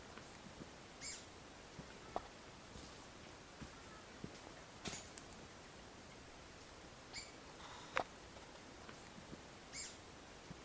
Bico-de-pimenta (Saltator fuliginosus)
Nome em Inglês: Black-throated Grosbeak
Localidade ou área protegida: Bio Reserva Karadya
Condição: Selvagem
Certeza: Gravado Vocal
Pepitero-Negro.mp3